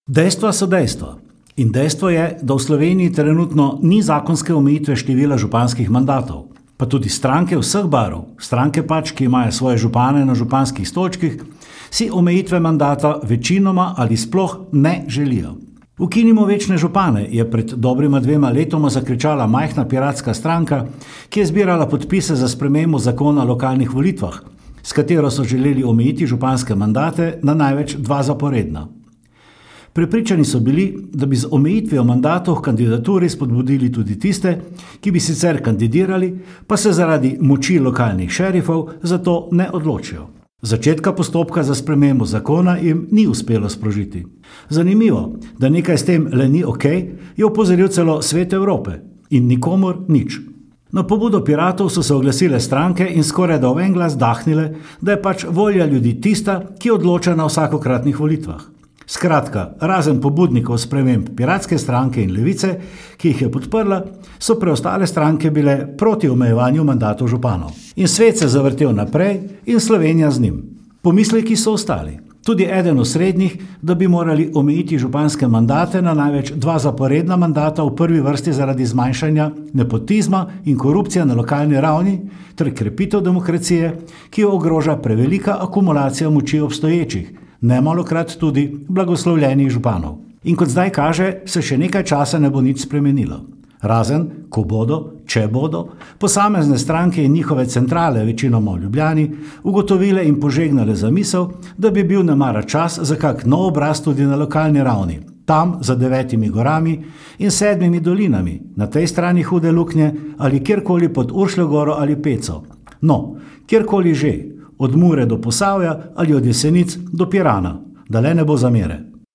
Komentar je stališče avtorja in ne nujno tudi uredništva.